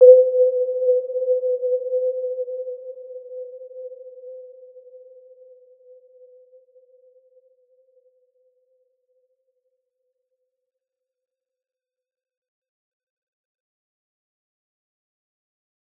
Warm-Bounce-C5-mf.wav